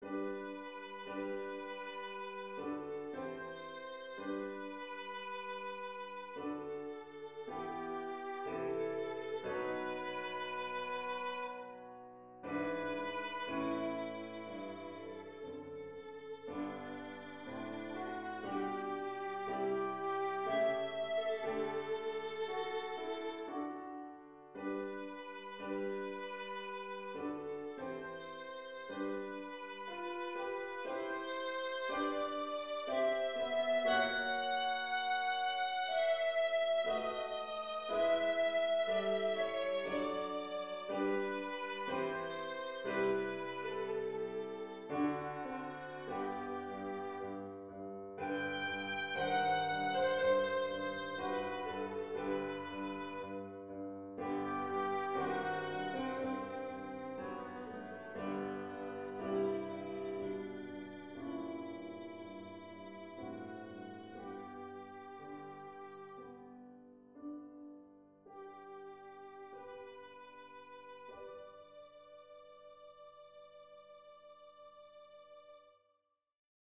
Жанр: Классическая музыка